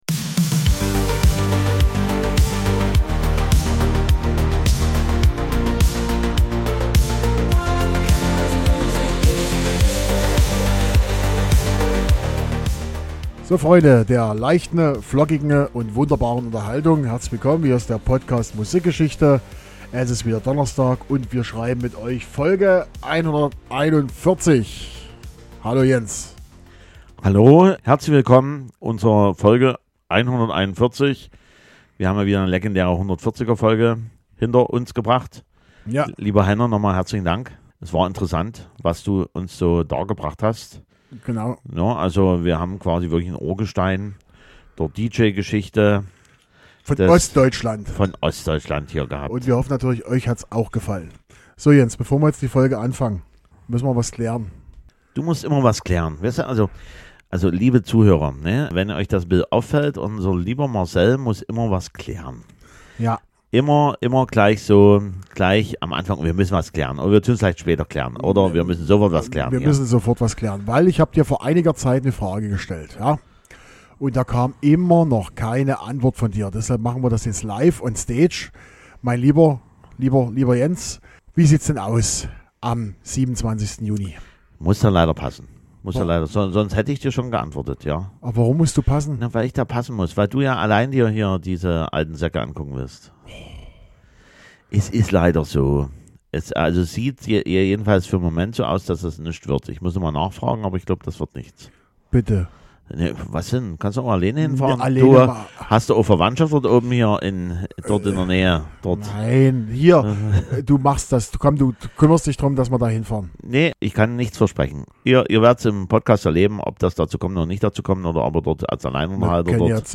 Musikalisch könnte diese Folge ein wenig eintönig sein, da 3 von 4 Titeln so richtig typische Nachkriegsschlager sind. Trotzdem ist die Geschichte hinter den Songs und den Künstlern sehr interessant.